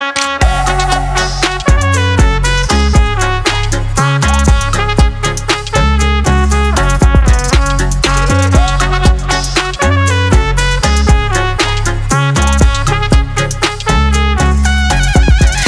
веселые
заводные
dance
без слов
труба
Музыка труб)